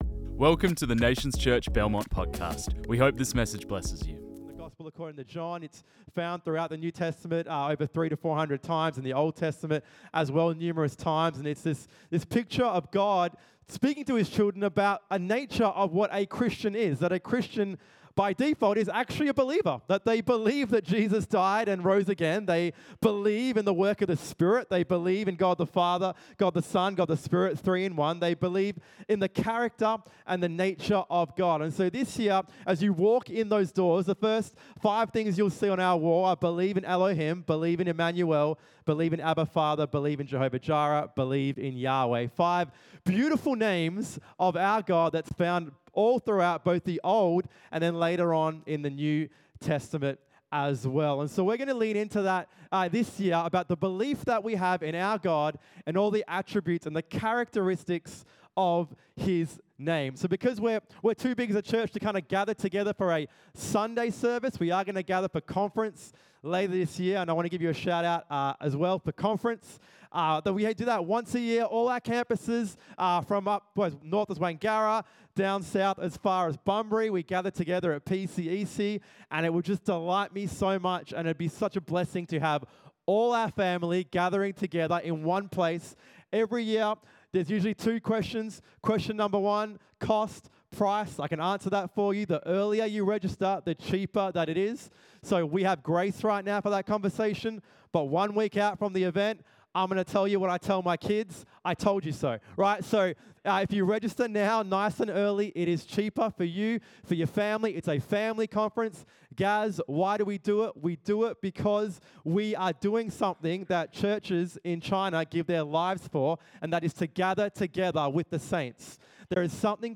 This message was preached on 23rd February 2025